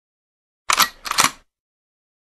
دانلود صدای پر کردن تفنگ 1 از ساعد نیوز با لینک مستقیم و کیفیت بالا
جلوه های صوتی